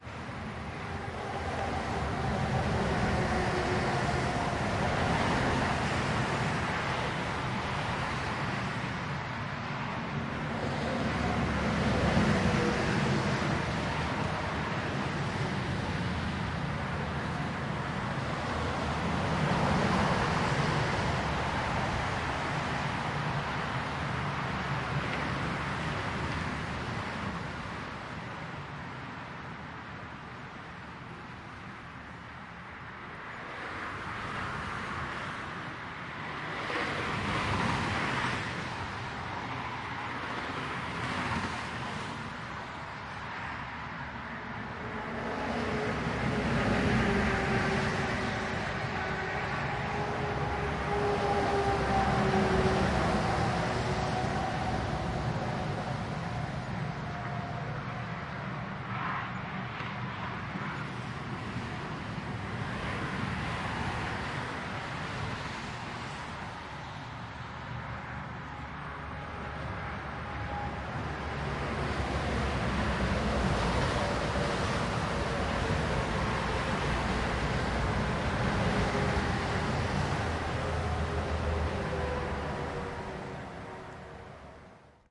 罗马的公路交通
描述：在星期天早晨在意大利罗马放大h4n立体声录音中等平安的中央广场。很好地捕获了一辆经过的摩托车以及声音和汽车。
Tag: 意大利 传递 摩托车 交通 罗马 人声鼎沸 现场记录